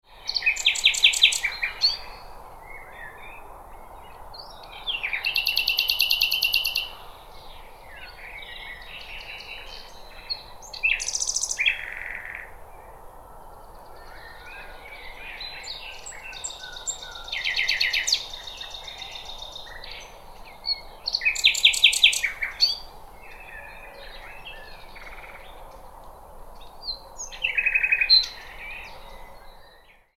Blackbird Call Sound
A wild bird sings in its natural environment. A relaxing sound from nature, perfect for creating a calming atmosphere.
Bird sounds.
Genres: Sound Effects
Blackbird-call-sound.mp3